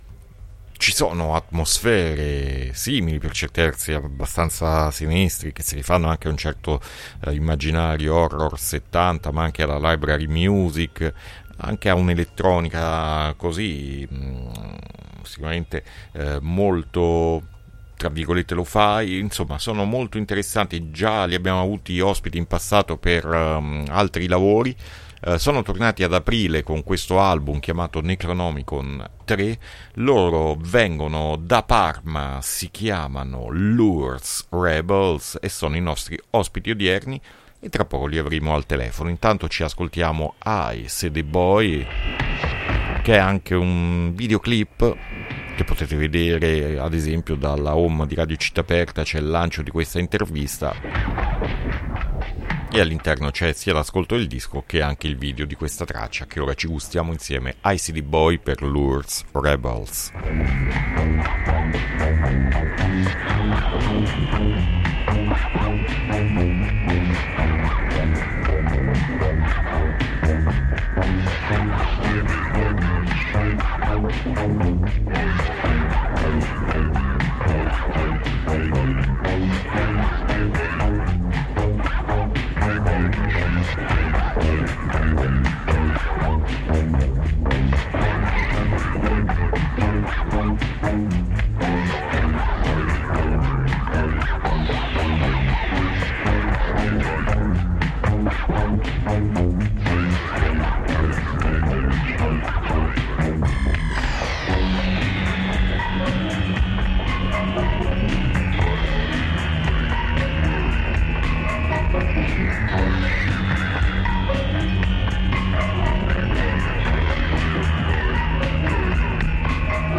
INTERVISTA LOURDES REBELS A PUZZLE 4-9-2023